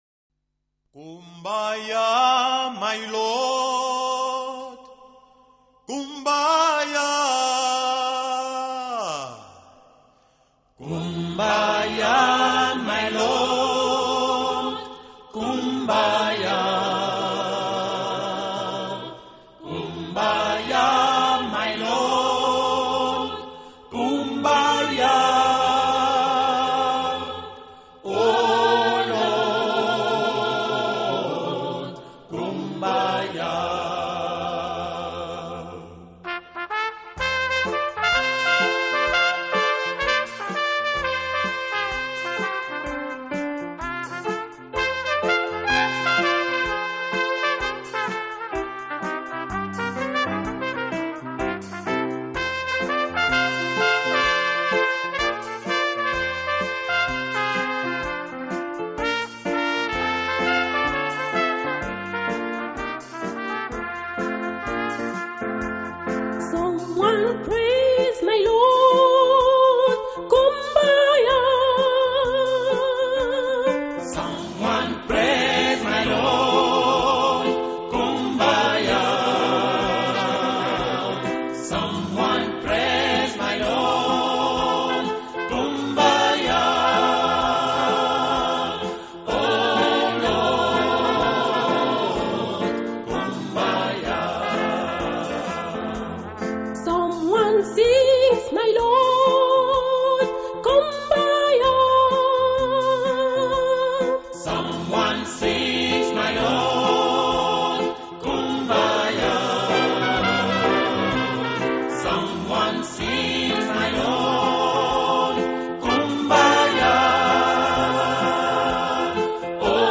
Cours d’harmonica – Kumbaya
Version avec harmonica diatonique accordé en Do (C).